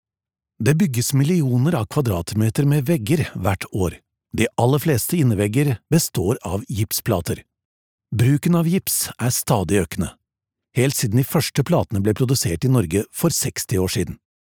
Tief, Vielseitig, Zuverlässig, Erwachsene, Warm
Audioguide